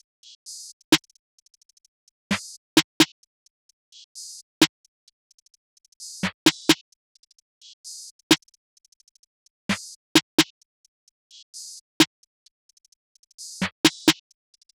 EN - Loop III (130 BPM).wav